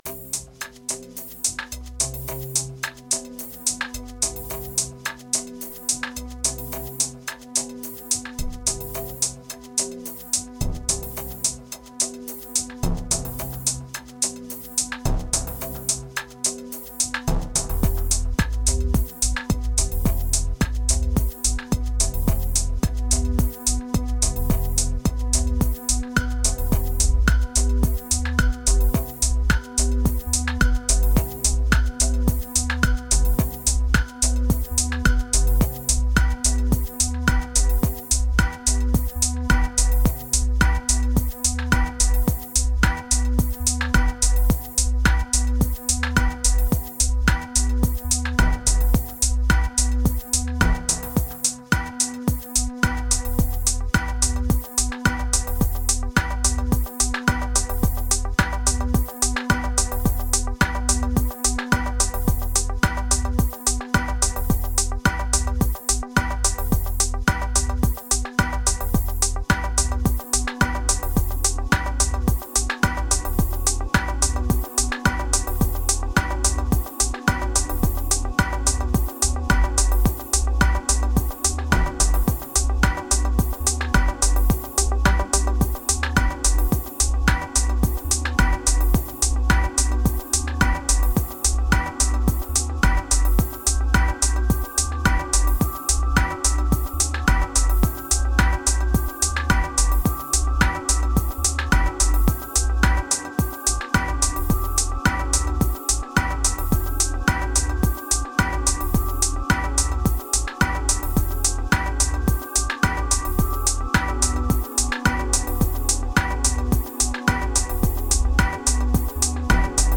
Kicks Techno Dub Lean Spring Ladder Riddim